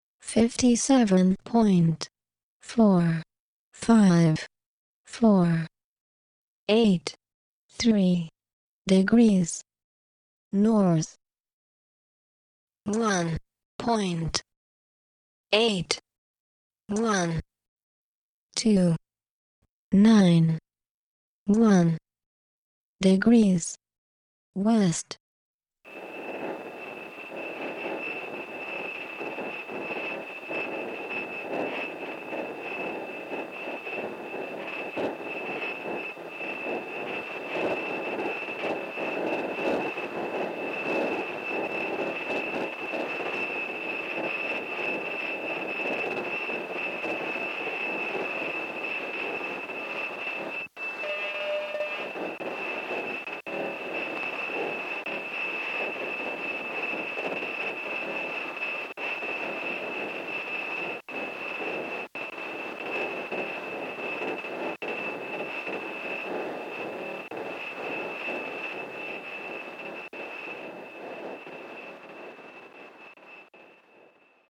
57°27’32 N – 1°48’39 W – Field Recordings